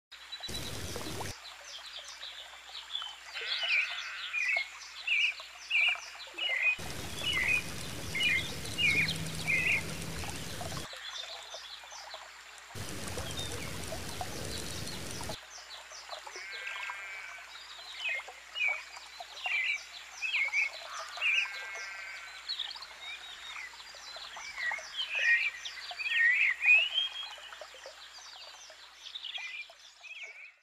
Звуки стрекозы
Стрекоза в природе подлетает и садится на куст